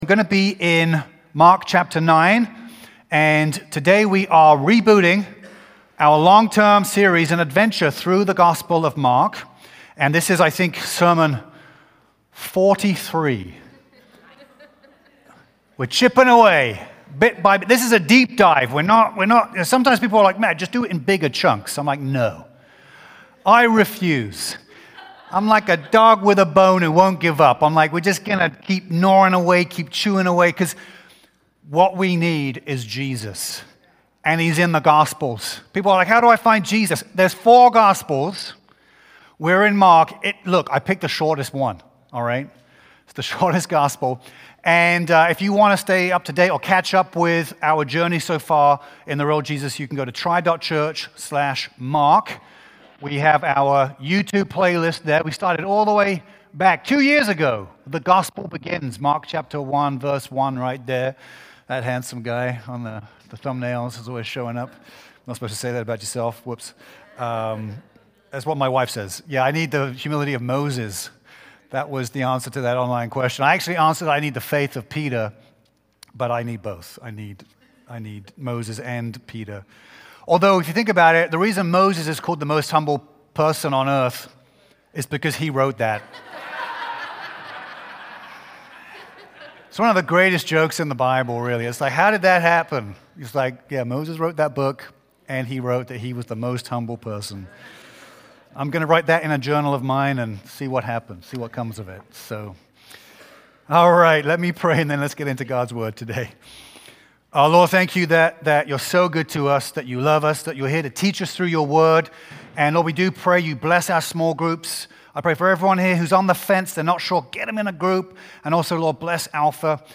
February-1-2026-Full-Sermon.mp3